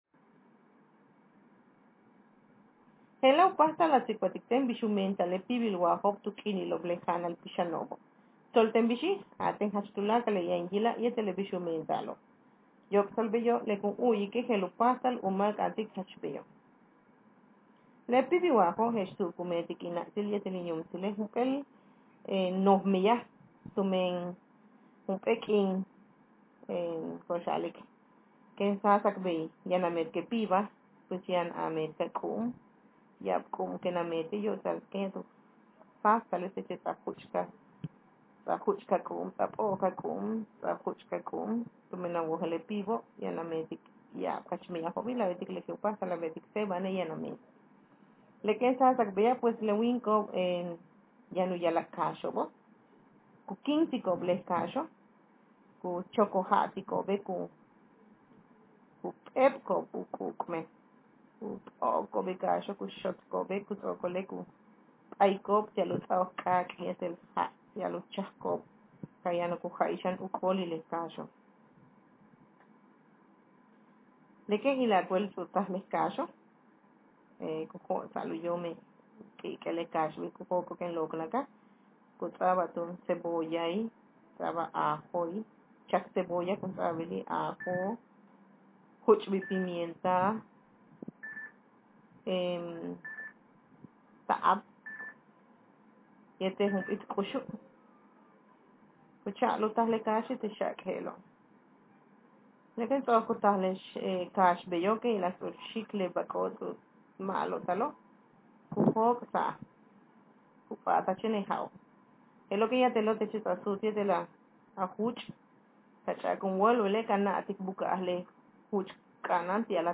Speaker sexf
Text genreprocedural